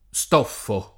stoffo [ S t 0 ffo ]